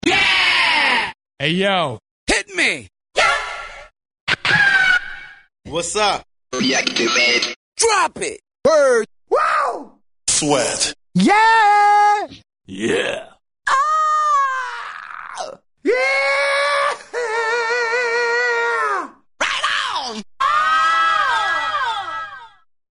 Freeform